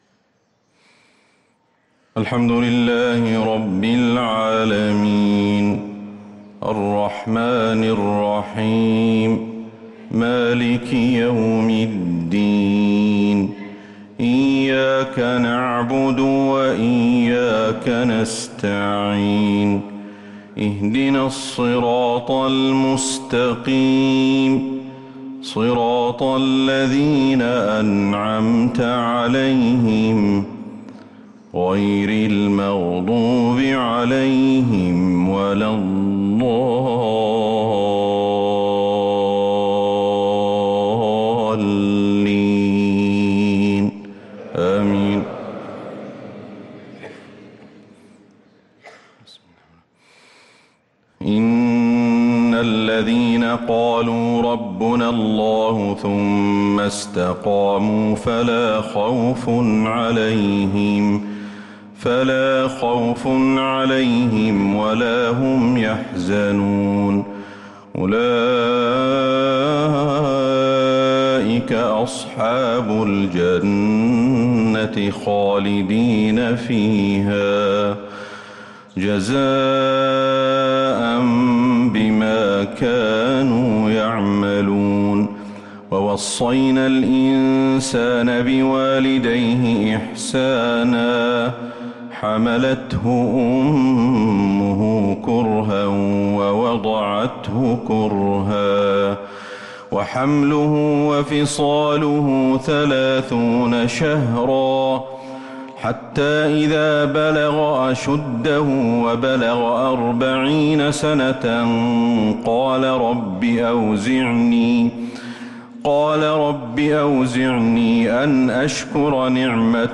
صلاة الفجر للقارئ أحمد الحذيفي 6 ربيع الآخر 1444 هـ
تِلَاوَات الْحَرَمَيْن .